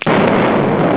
crash.wav